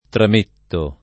vai all'elenco alfabetico delle voci ingrandisci il carattere 100% rimpicciolisci il carattere stampa invia tramite posta elettronica codividi su Facebook tramettere [ tram % ttere ] v.; trametto [ tram % tto ] — coniug. come mettere — cfr. mettere